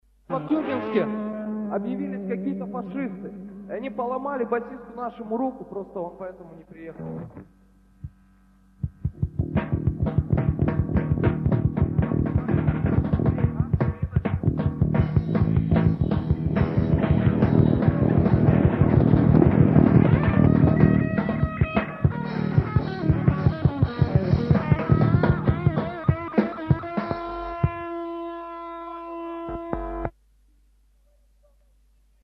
вокал, бас-гитара
соло-гитара
ударные